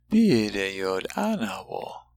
When paragraphs of Láadan are given, sound files will be provided for the entire paragraph as well as each sentence.